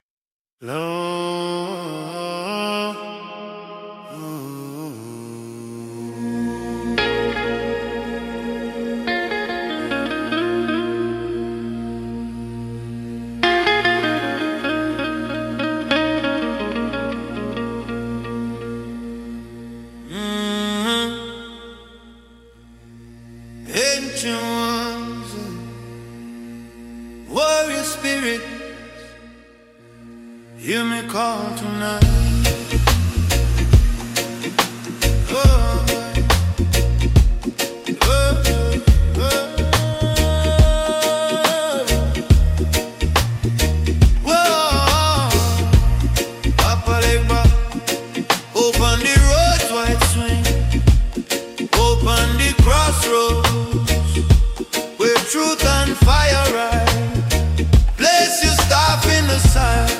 🎧 Genre: Deep Spiritual ⏱ Length: 3:32